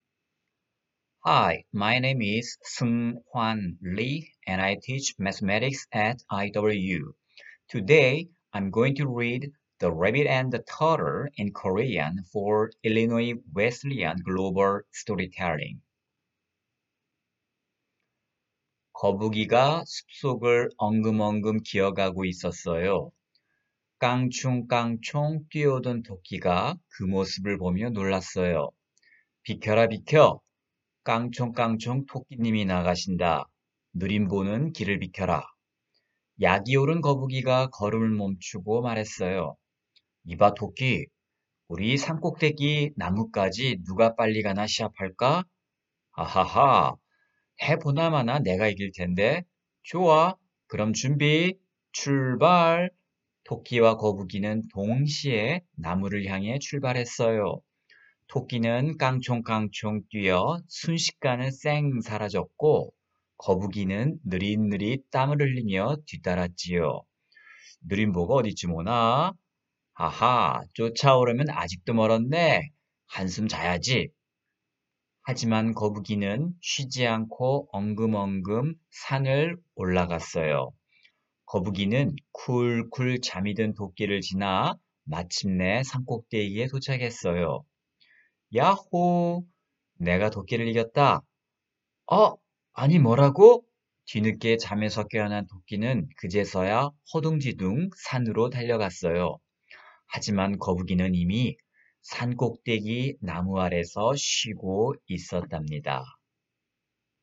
Oral History Item Type Metadata